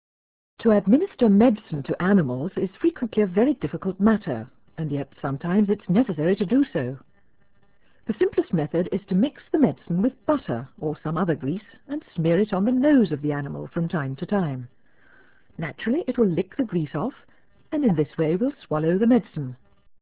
I wanted to see what it would sound like in comparison to a really early streaming audio codec, realaudio 1.0
This was seen as "14.4" audio, for 14.4kb/s dialup in the mid-90s.
female_ref-ra.wav